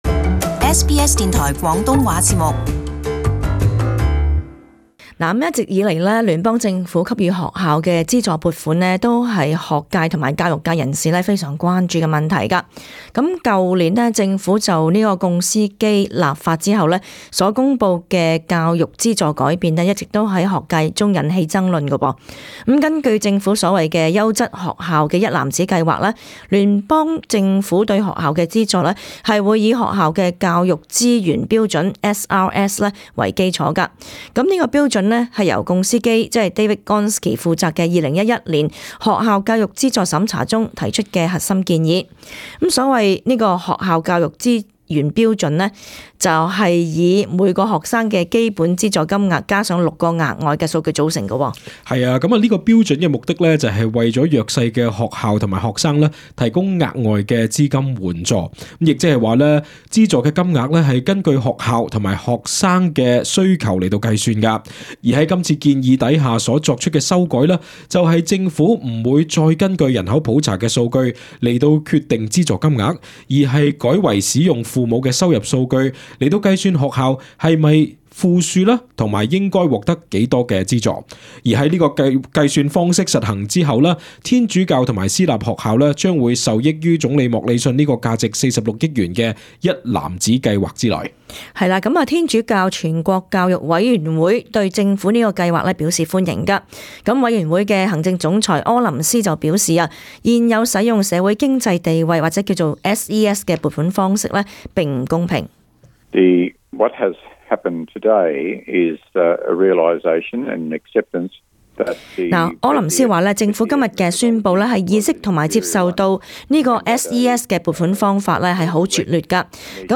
【時事報導】總理莫里遜相信數以十億元的新撥款計劃可帶來和平，但新州教育廳長表示反對。